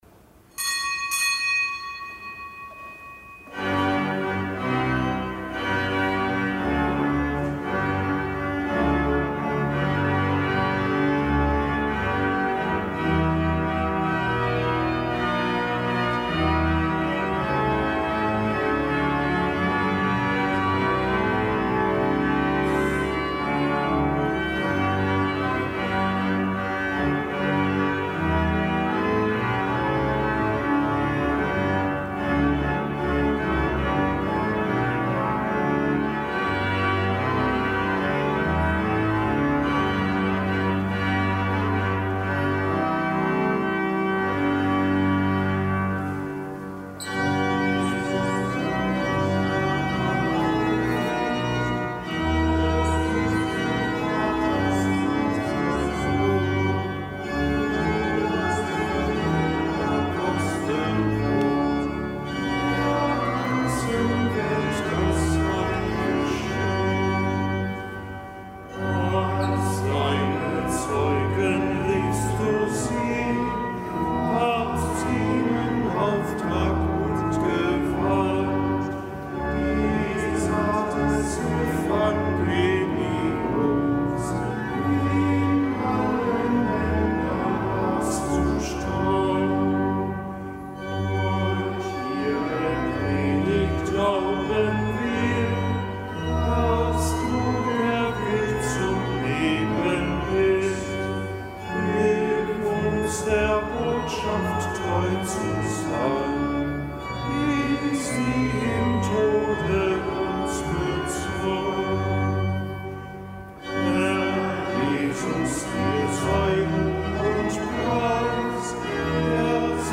Kapitelsamt am Gedenktag des Heiligen Johannes